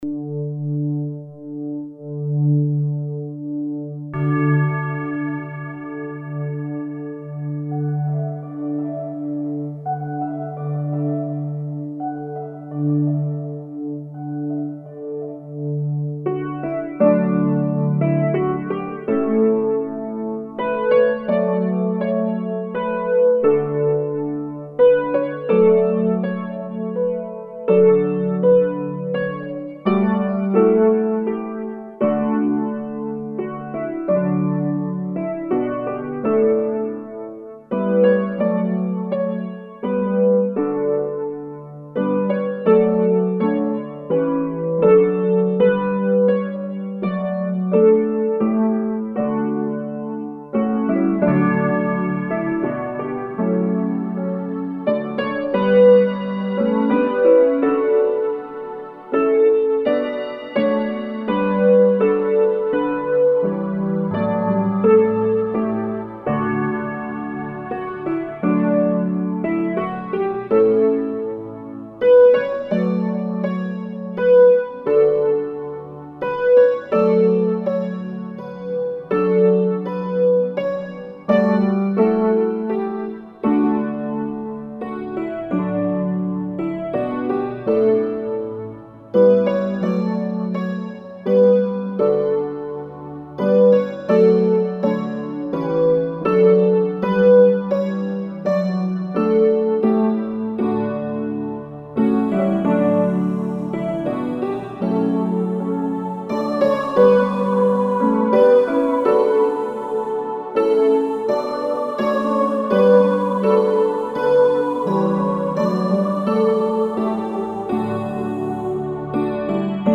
Chant de Noël, Angleterre (Royaume-Uni).
Karaoké
(instrumental)